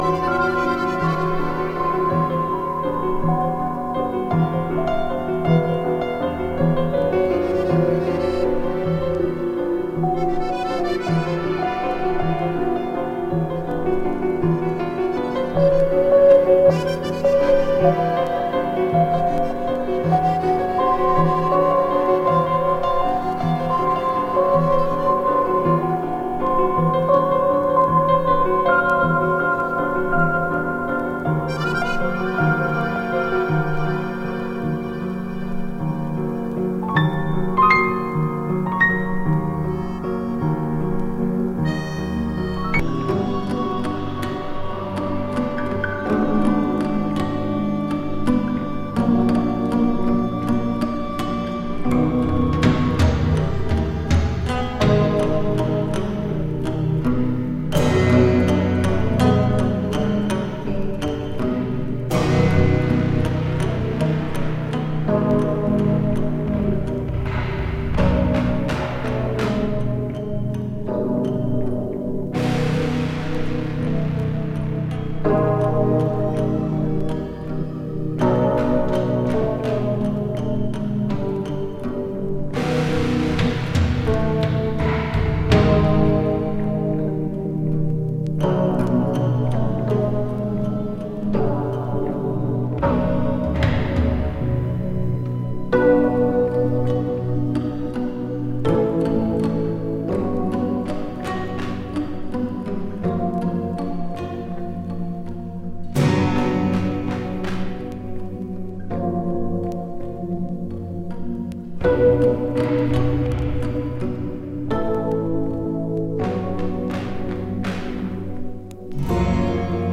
オランダ産プロッグ・ジャズ・ロック
スムース・ディスコティーク
ミニマム・アフロ・ディスコ「DRUMS 2001 CARNIVAL」「HAPPY FEELING RHYMES」
欧州アフロ・グルーブ。
ビッグバンド・イージーリスニン・グルーブ「OCHOS RIOS」
ネットリ電気バイオリン・メロウ・フュージョン